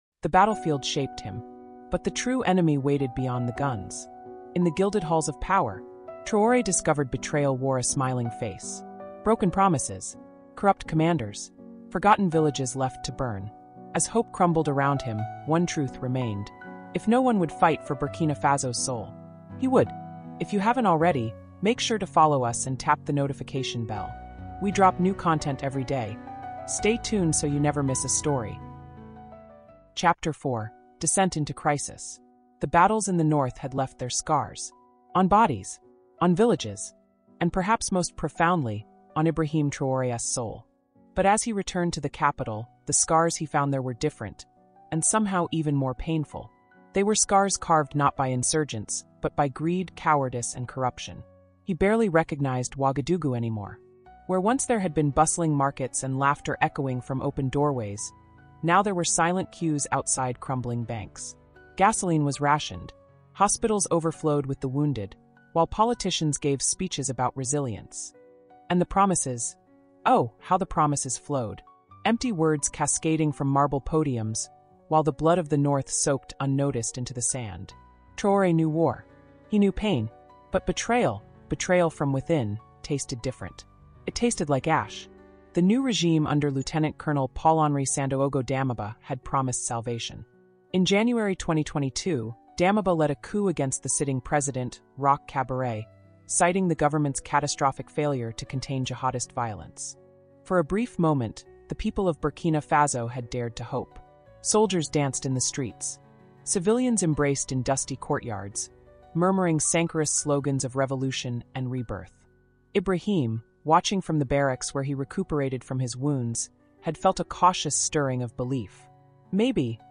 Captain Ibrahim Traoré, The Rising Lion of the Sahel, Pan-African heroes, Black history audiobook, Sahel revolution, cultural empowerment stories, African leaders audiobook, Burkina Faso revolution, rebellion audiobook, Caribbean culture, true African heroes, political corruption audiobook, audiobook listeners, African history storytelling, rise of Captain Traoré, inspirational black leaders, victory and betrayal story , history experts, caribbean history, travel experts, caribbean music, island vibes, jamaica ,history , history of the caribbean